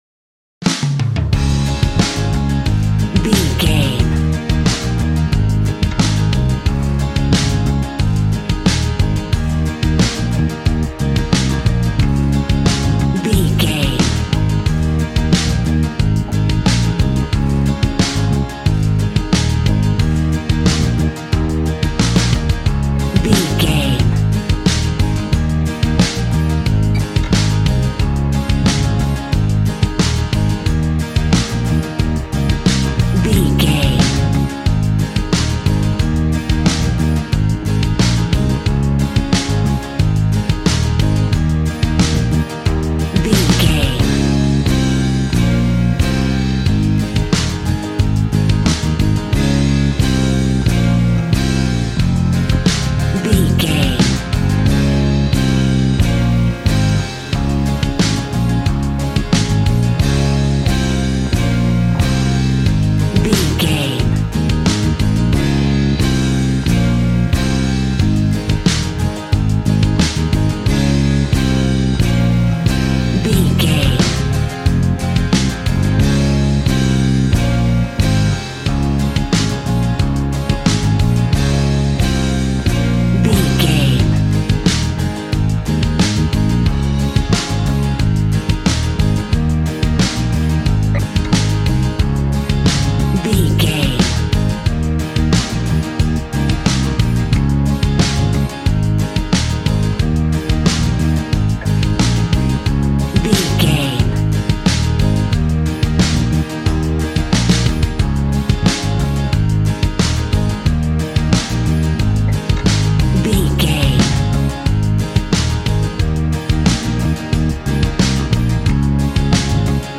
Ionian/Major
A♭
groovy
happy
electric guitar
bass guitar
drums
piano
organ